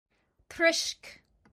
Each week, SAY IT IN IRISH features an Irish or Hiberno-English word or phrase, exploring its meaning, history and origins – with an audio recording by a native Irish speaker from Cork so you can hear how it’s pronounced.
Troisc – pronounced roughly trishk